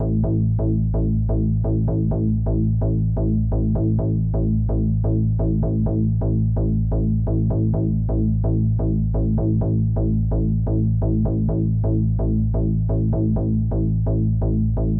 滤波后的低音循环 128BPM A调
描述：这是一个A调的128BPM的房子低音循环。